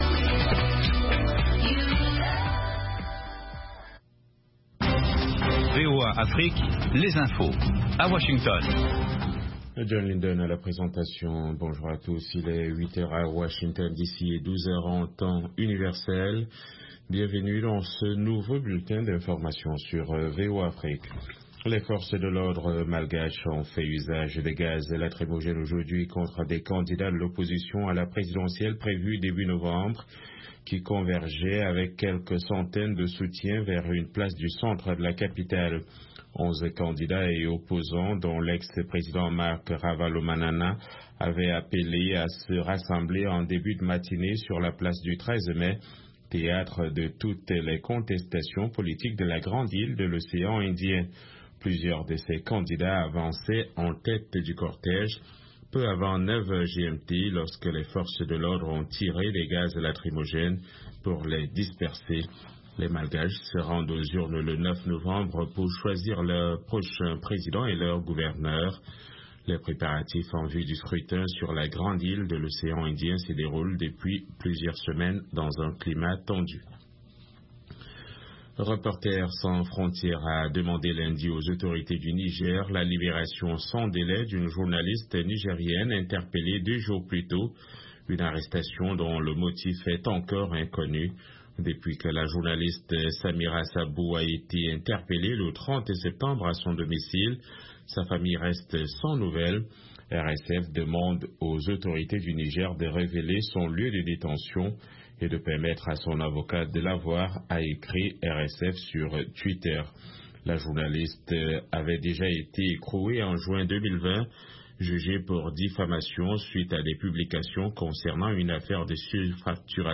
Bulletin d'information de 16 heures